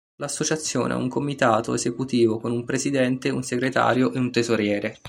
/e.ze.kuˈti.vo/